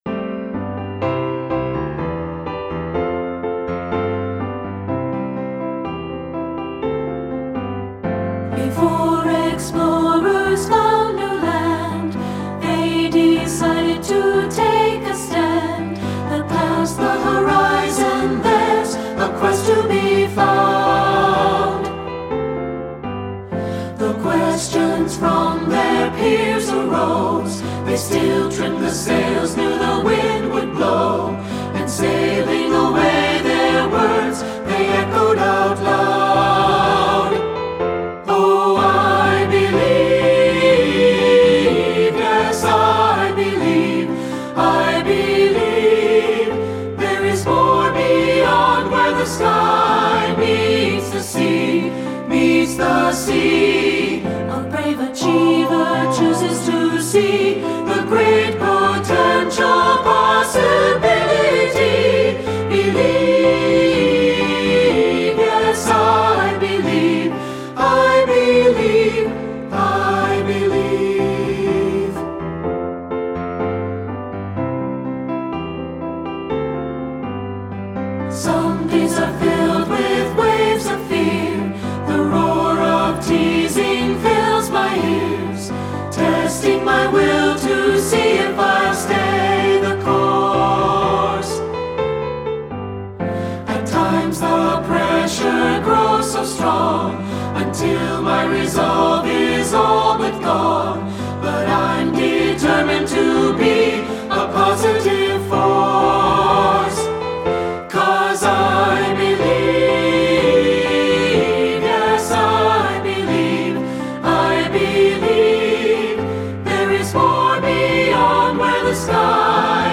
• Piano
Studio Recording
Ensemble: Three-part Mixed Chorus
Accompanied: Accompanied Chorus